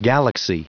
Prononciation du mot galaxy en anglais (fichier audio)
Prononciation du mot : galaxy